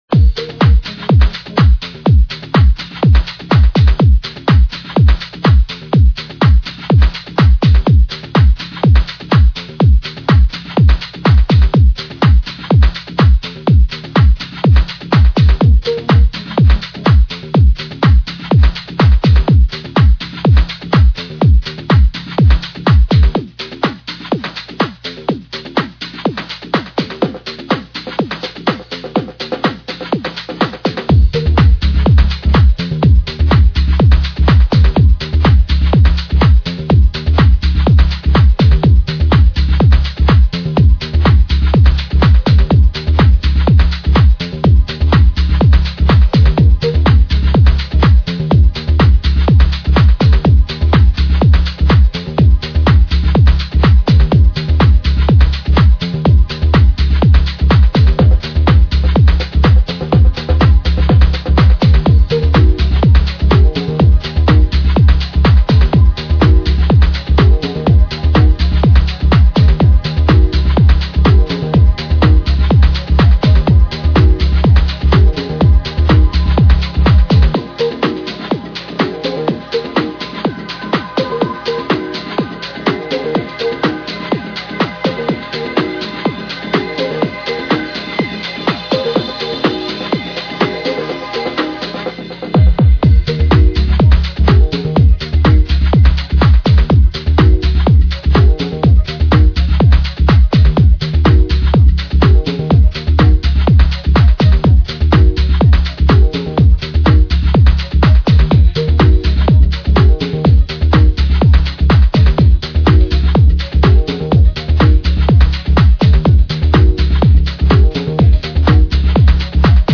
vibrant synths and buoyant house rhythms